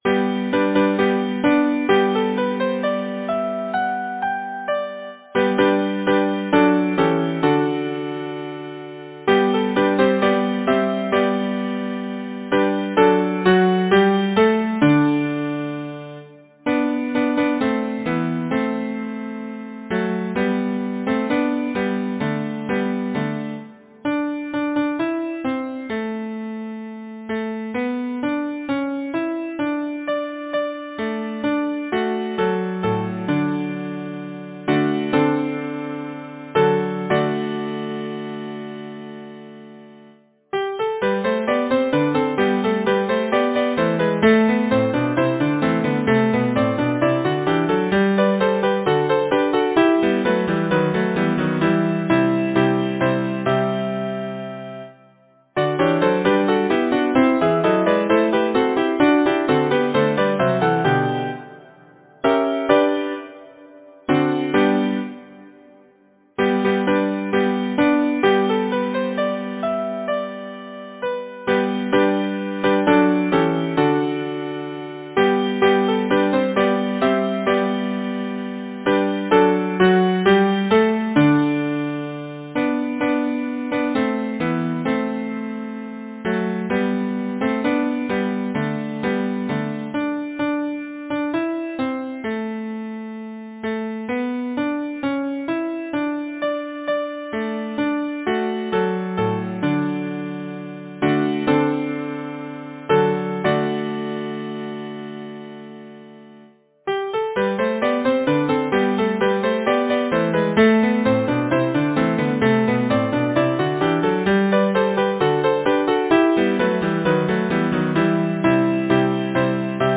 Number of voices: 4vv Voicing: SATB Genre: Secular, Partsong
Language: English Instruments: Piano